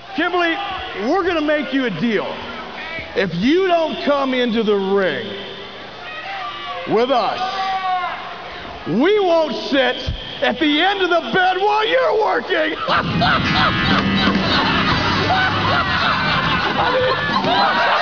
Bischoff would sit and
tell really bad jokes to canned laughter.
bischoffhaha.wav